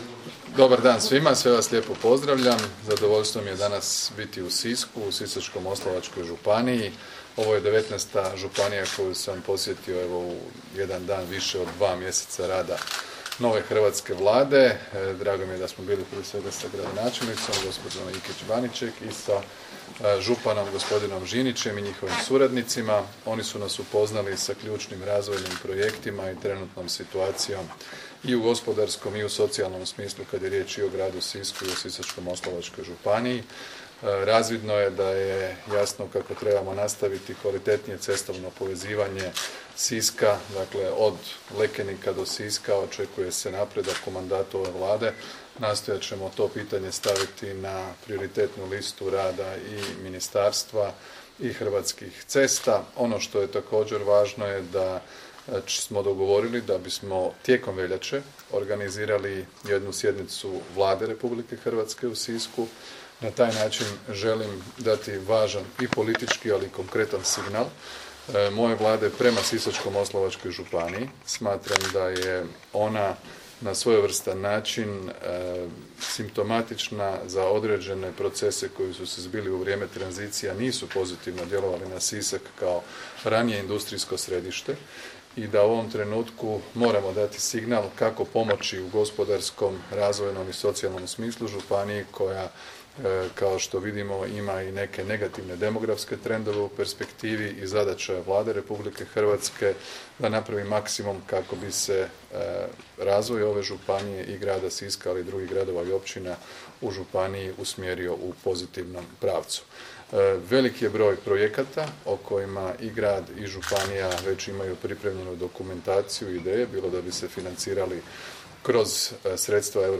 Predsjednik Vlade RH Andrej Plenković: